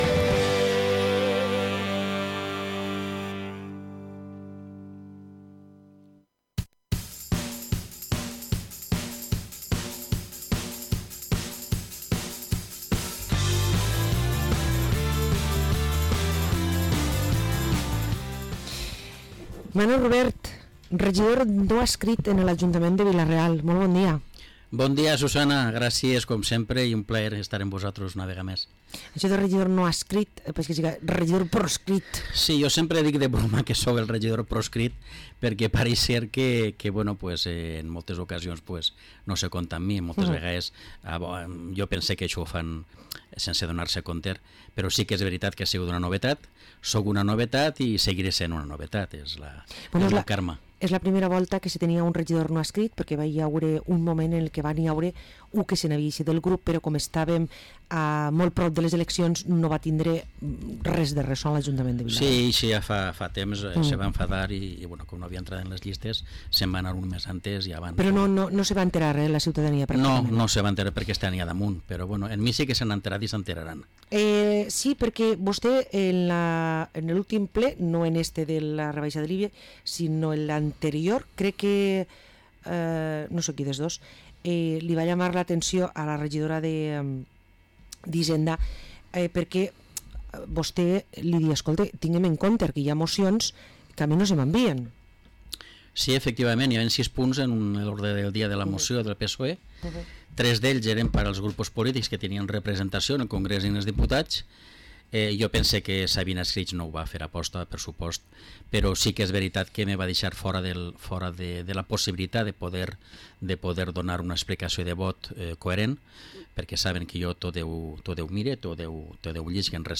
Parlem amb Manu Rubert, regidor no adscrit a l´Ajuntament de Vila-real